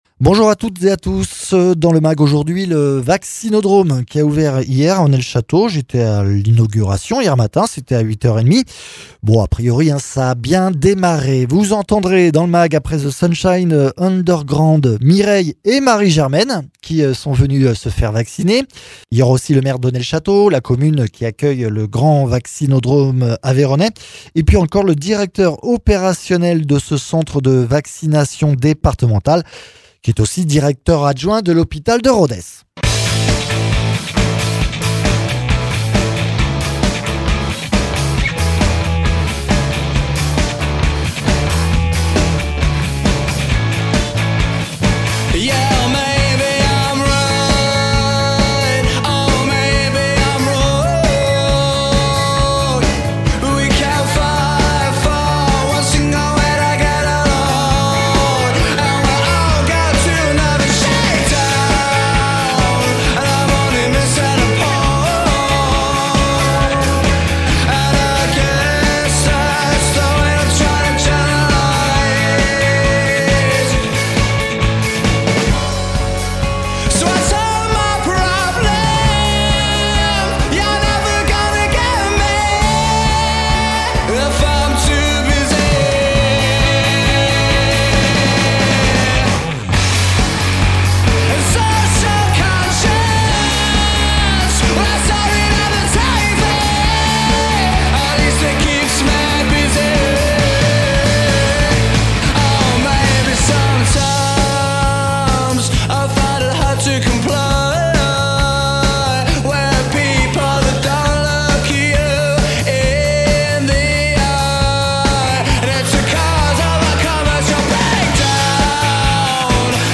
Jean Philippe Keroslian, maire d’Onet le Chateau